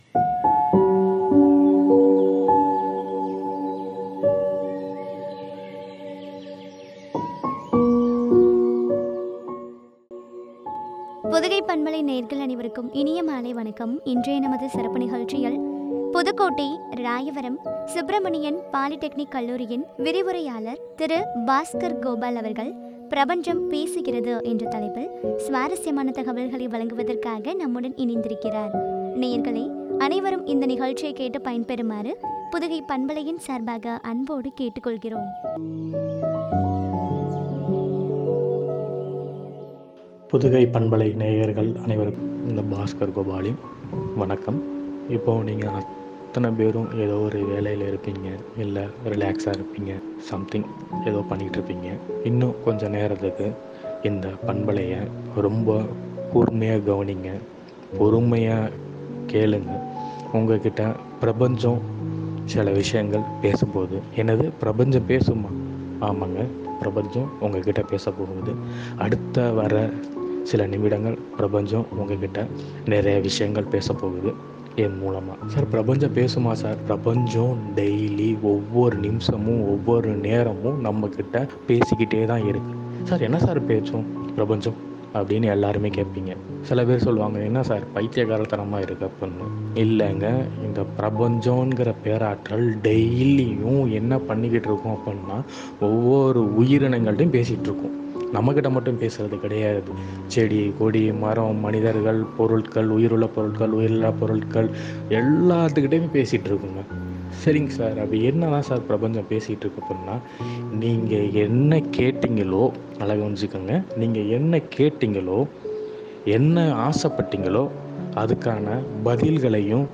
பிரபஞ்சம் பேசுகிறது என்ற தலைப்பில் வழங்கிய உரையாடல்.